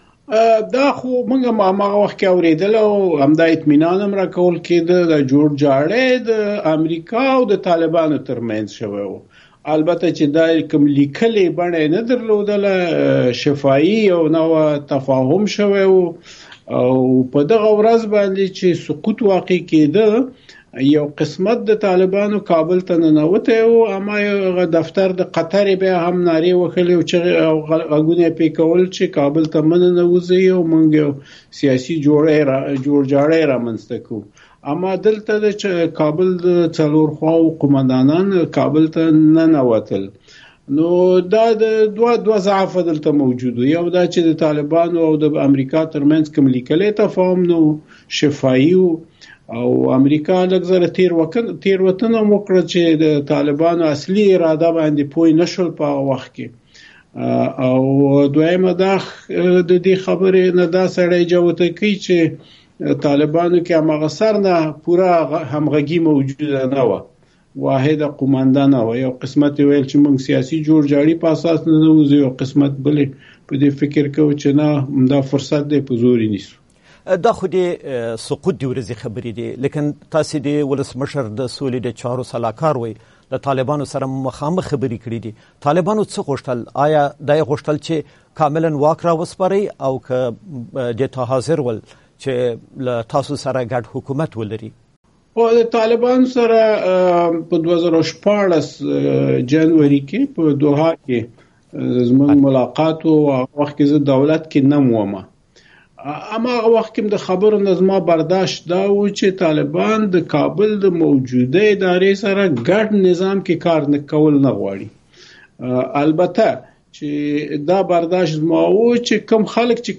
له محمد عمر داودزي سره مرکه